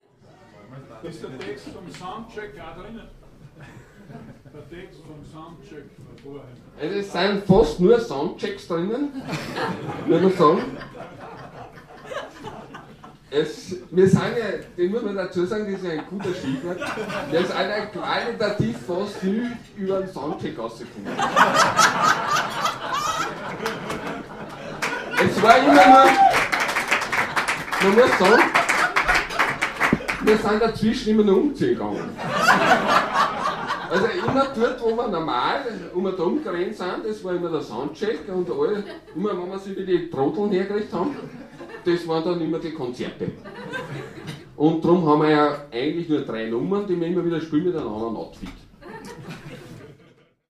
KommentaBuach.mp3